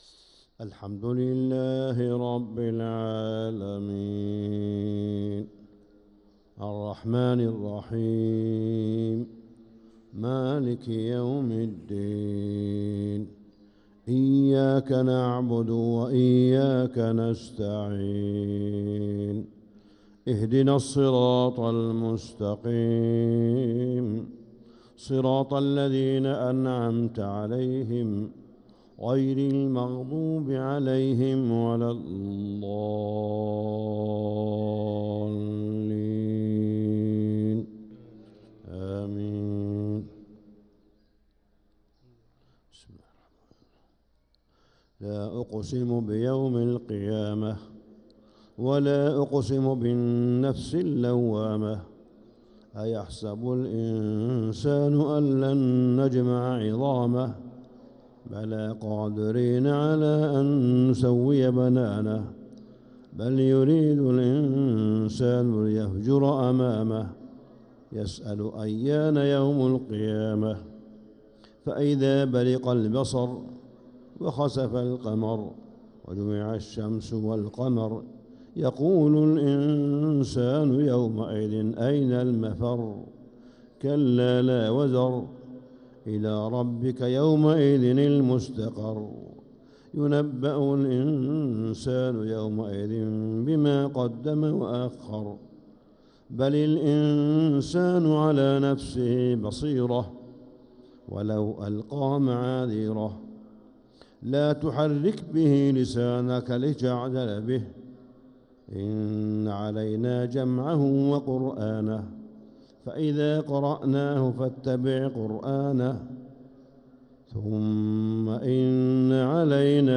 فجر الأحد 2-9-1446هـ سورة القيامة كاملة | Fajr prayer Surat al-Qiyamah 2-3-2025 > 1446 🕋 > الفروض - تلاوات الحرمين